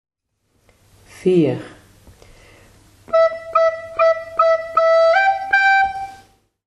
Opa’s notenboom en een team van 4 zingende cachers leidt tot een puzzel, die om noten draait.
Ieder fragmentje is het begin van een, nog steeds bekend Nederlandstalig kinderliedje uit de oude doos.